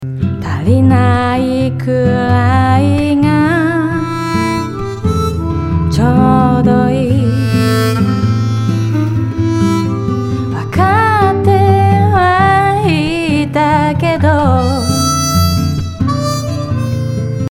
同じ分暗くなるかと思いきや、いたって普通な感じになりましたね。